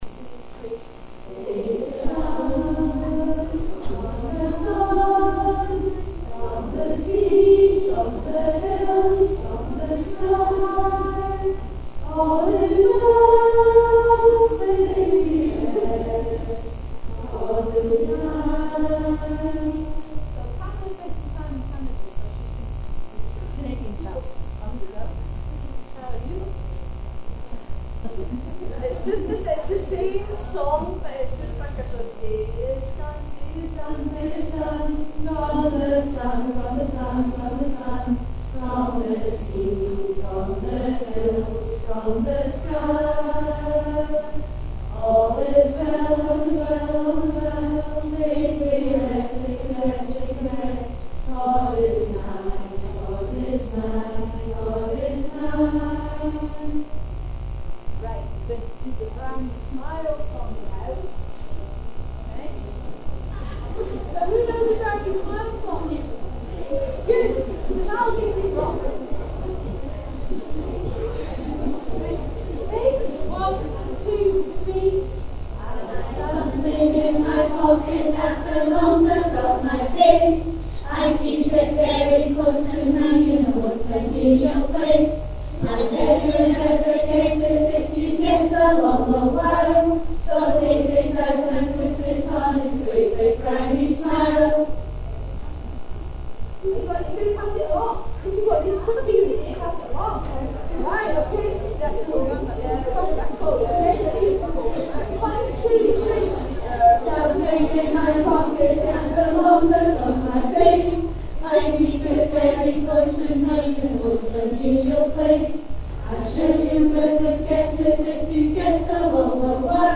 Thinking Day - 19 February 2019
closing songs.